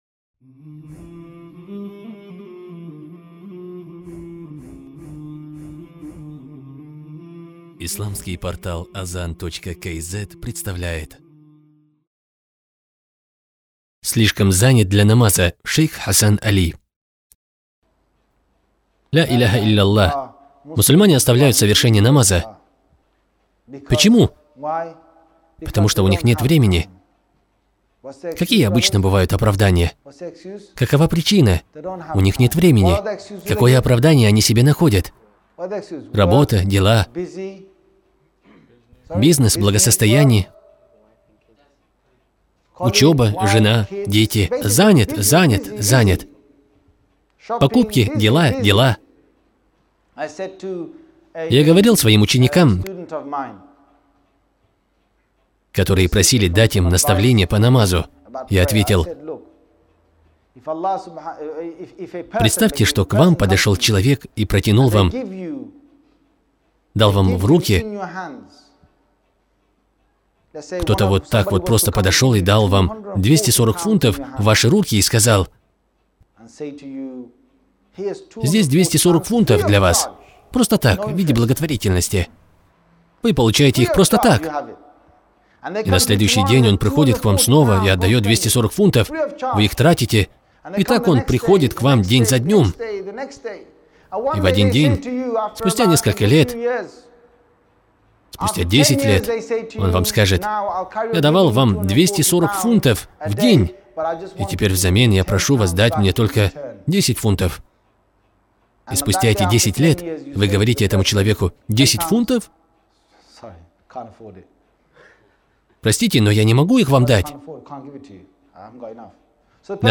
Лектор